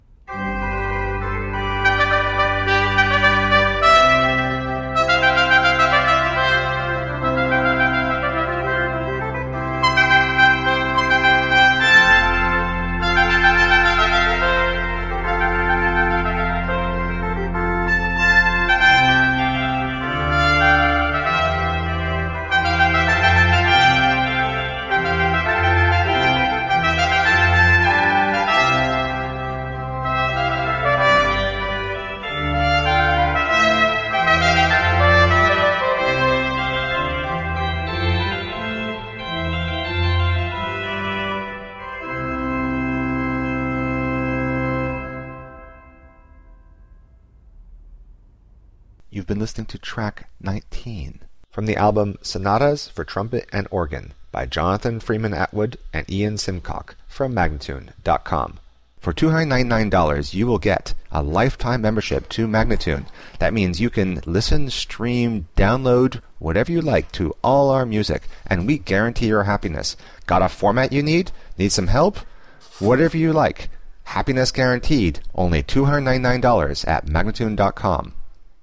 Brilliant baroque masterpieces in virtuoso style.
Classical, Chamber Music, Baroque, Instrumental